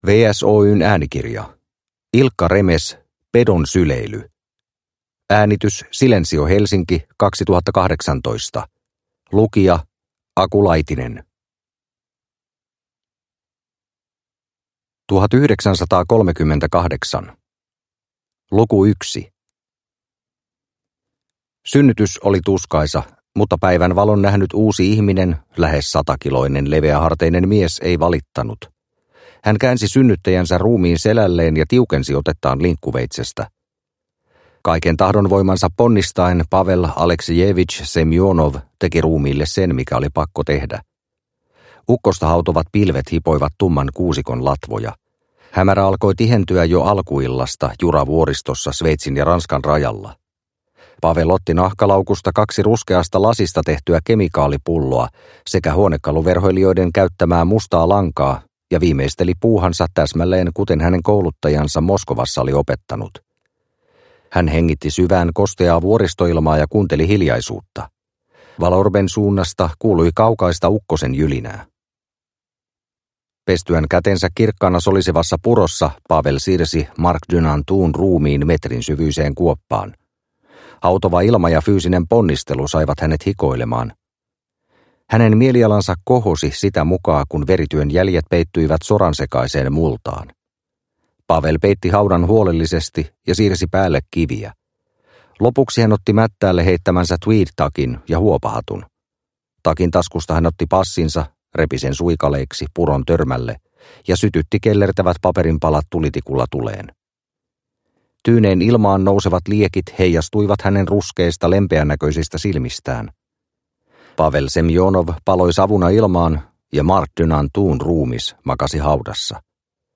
Pedon syleily – Ljudbok – Laddas ner